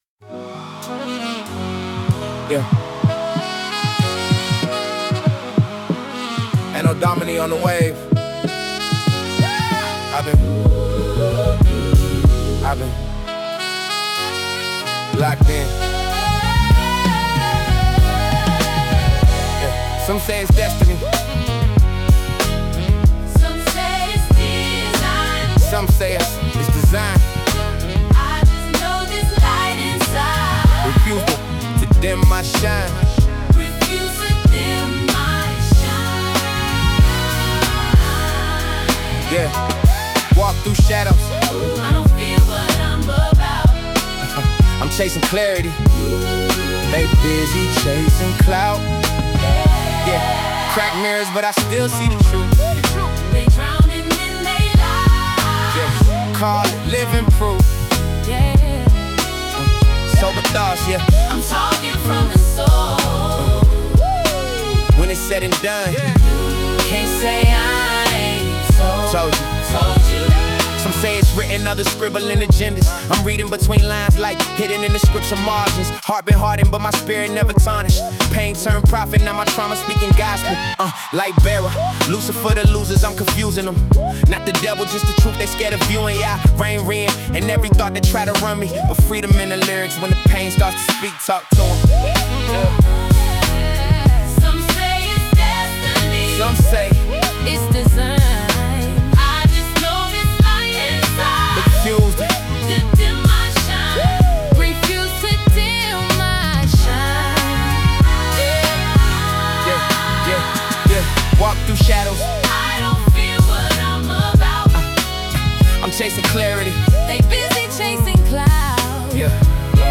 elevated, game-spitting energy